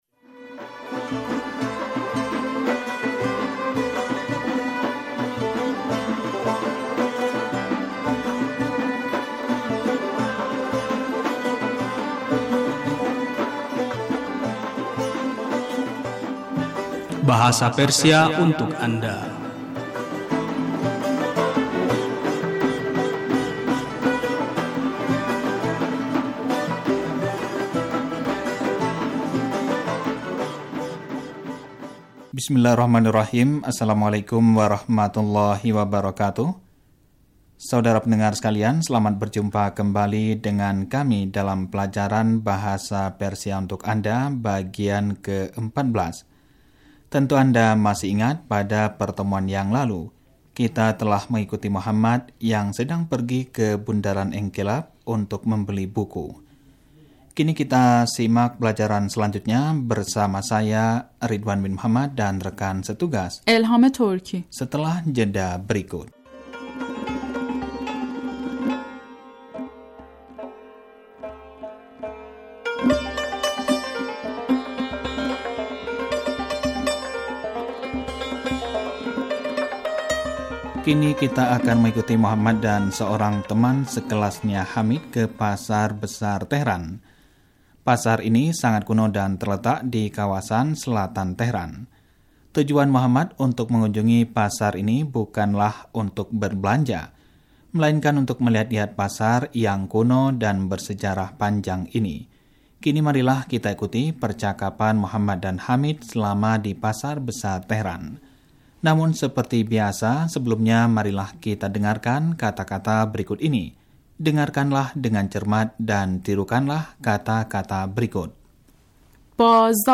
Dengarkanlah dengan cermat dan tirukanlah kata-kata berikut.
Kini, marilah kita mendengarkan percakapan antara Muhammad dan Hamid di Pasar Besar Tehran.